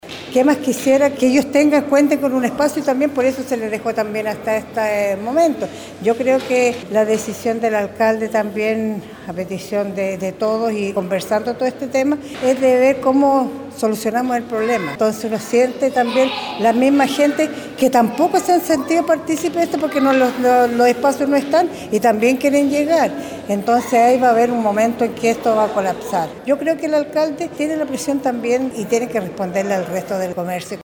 Por su parte, la concejal (DC), María Soledad Uribe, indicó que es necesario encontrar una solución que beneficie a la ciudadanía y al comercio en general, antes de que esto colapse.